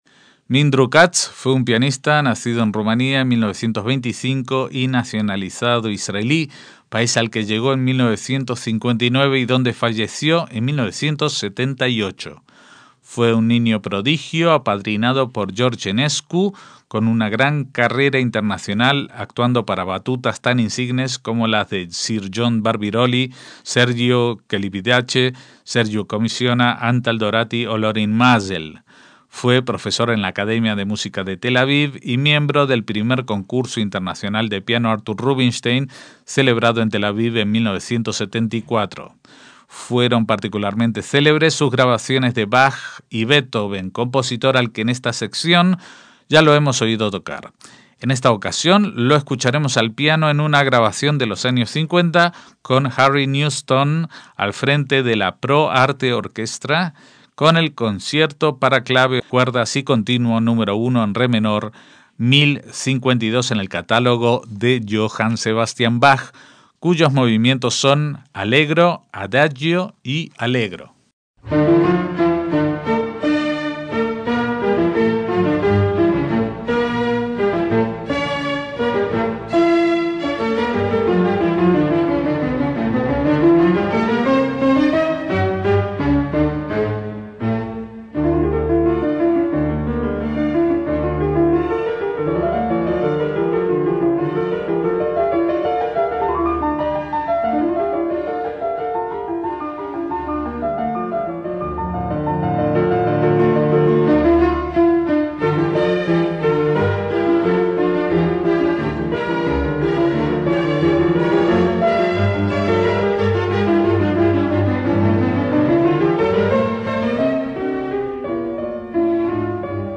MÚSICA CLÁSICA - Mindru Katz fue un pianista israelí nacido en Rumania en 1925 y fallecido en 1978.